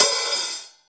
paiste hi hat8 half.wav